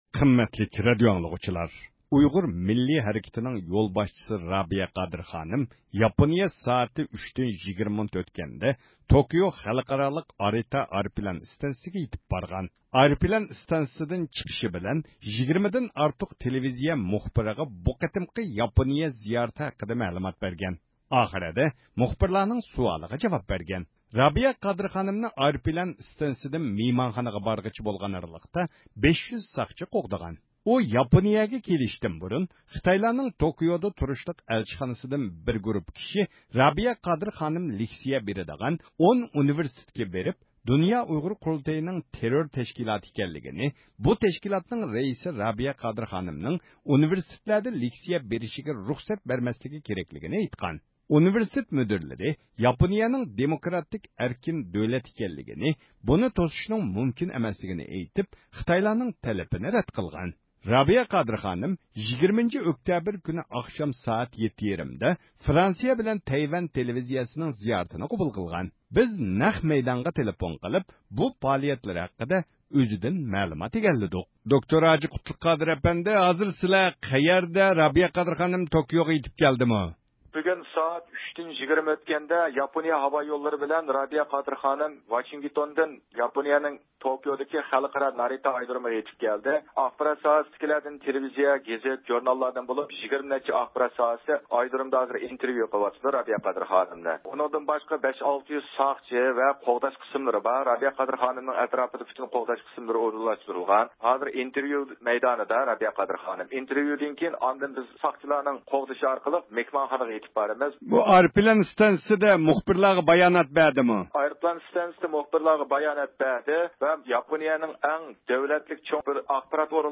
بىز نەق مەيدانغا تېلېفون قىلىپ، بۇ پائالىيەتلىرى ھەققىدە ئۈزىدىن مەلۇمات ئىگىلىدۇق.